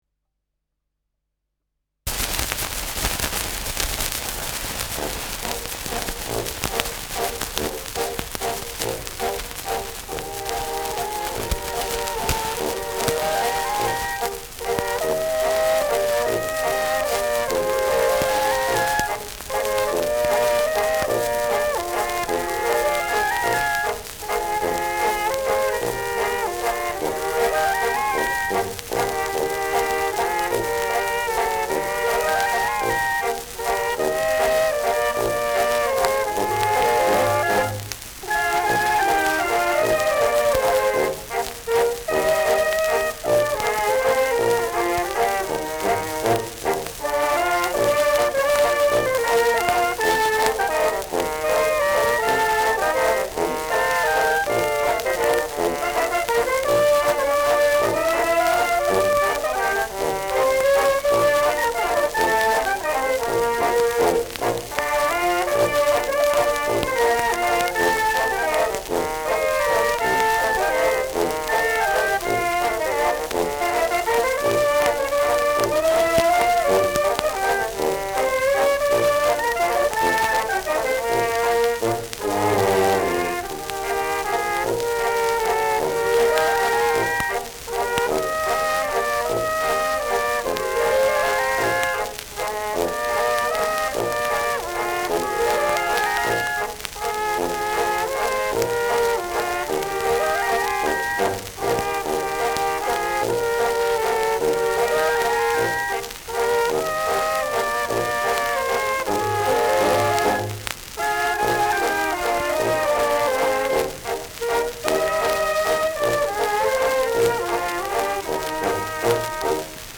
Schellackplatte
Tonrille: Abrieb : Kratzer 8 Uhr
präsentes Rauschen : Knistern
Böhmische Bauernkapelle (Interpretation)